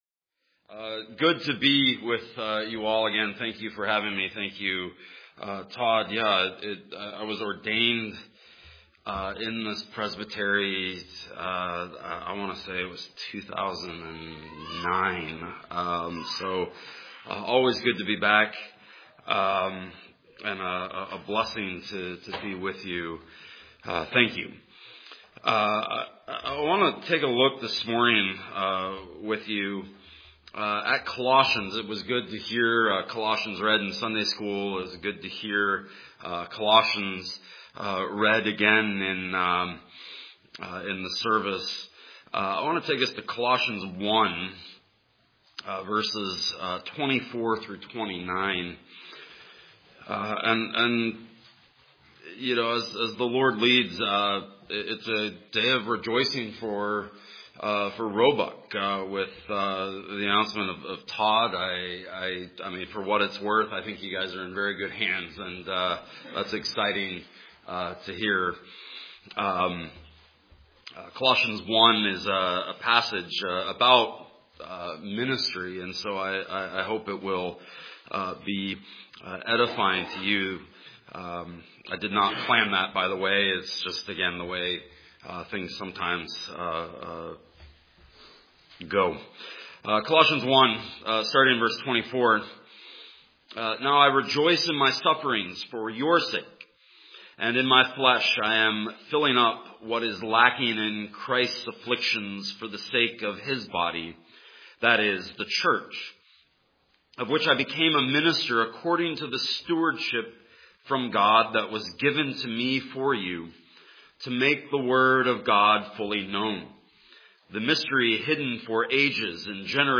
Colossians 1:24-29 Service Type: Sunday Morning Colossians 1:24-29 The Apostle Paul describes ministry as suffering for the sake of the church.